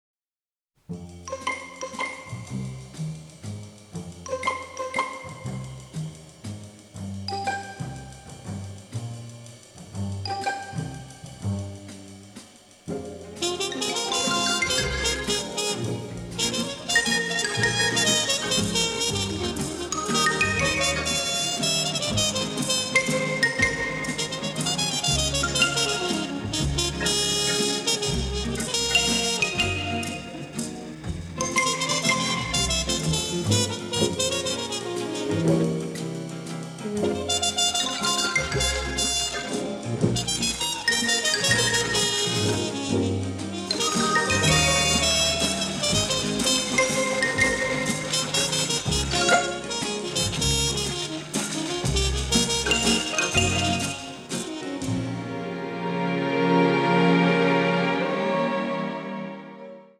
vibrant, funny, powerfully melodic
The recording took place at CTS Studios in Bayswater